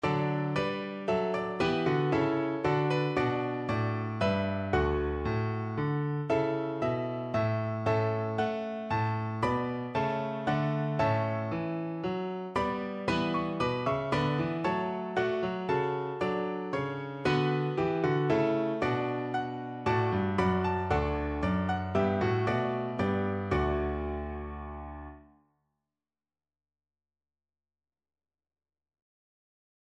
Free Sheet music for Piano Four Hands (Piano Duet)
D major (Sounding Pitch) (View more D major Music for Piano Duet )
3/4 (View more 3/4 Music)
Traditional (View more Traditional Piano Duet Music)